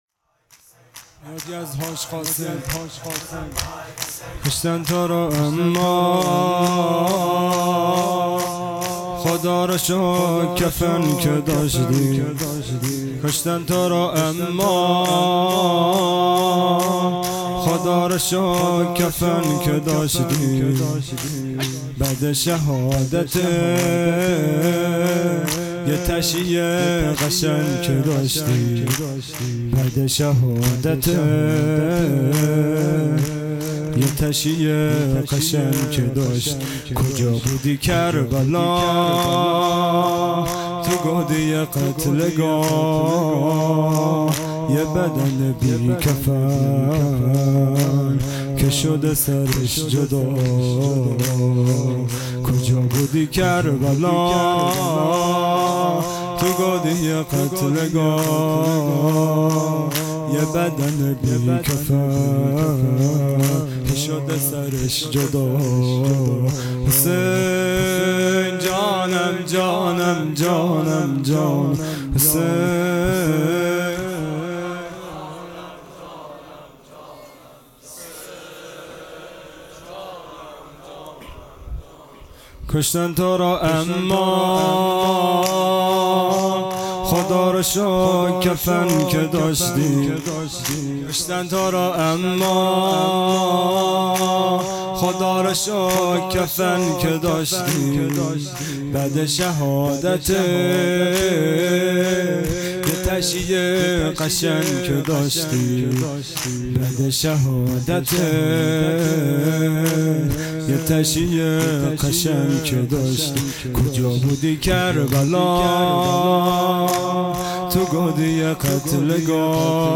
خیمه گاه - هیئت بچه های فاطمه (س) - شور | کشتن تو رو اما
فاطمیه دوم (شب اول)